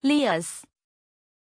Aussprache von Lias
pronunciation-lias-zh.mp3